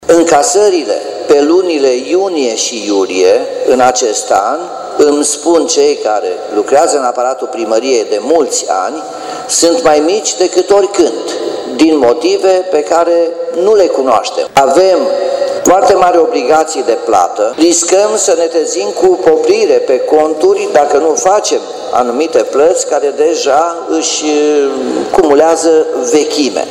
Primarul Nicolae Robu a recunoscut, cu ocazia dezbaterii unui proiect de rectificare a bugetului, că în visteria orașului au intrat bani puțini, în condiţiile în care cerințele de plată sunt foarte mari.